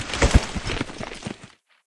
PixelPerfectionCE/assets/minecraft/sounds/mob/horse/land.ogg at mc116